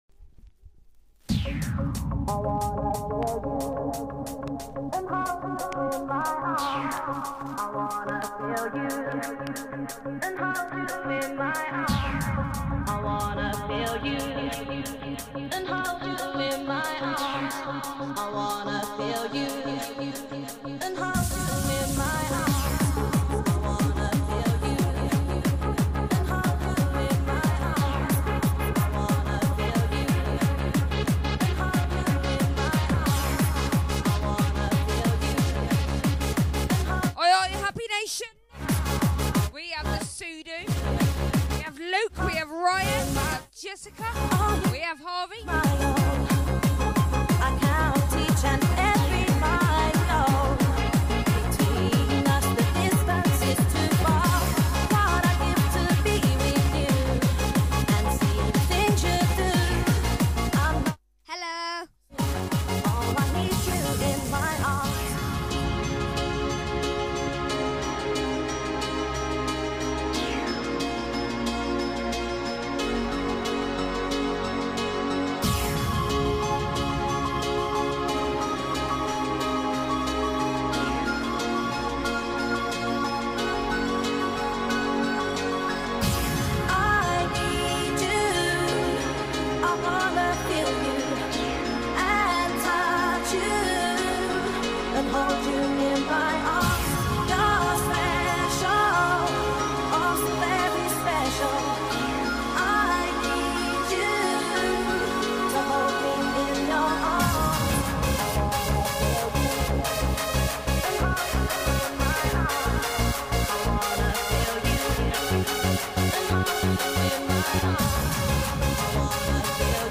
The kids get on the mic!
Happy Hardcore Vocals Oldskool